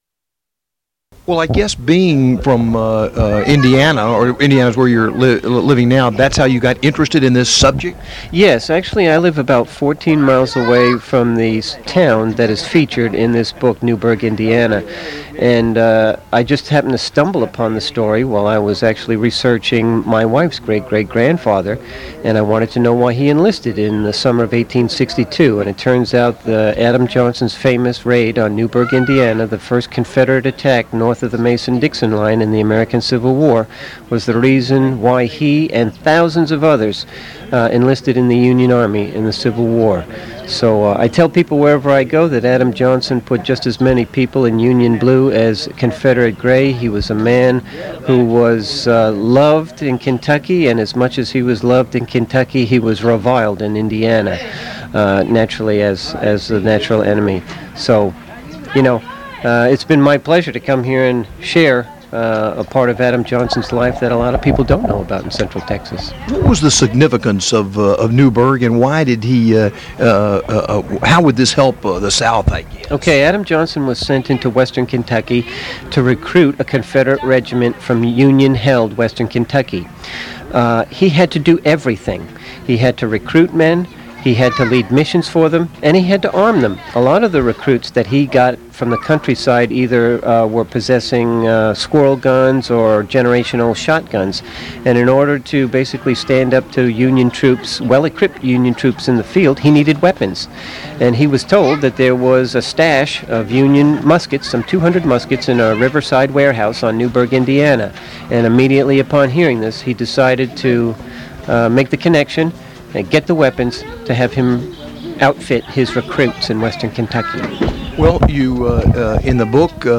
Radio interview
The interview is about 10 minutes long.
You'll hear some people in the background who were watching me as the interview was taking place.
Recorded interview later broadcast on air.
October 8, 2005 at Fort Croghan, Texas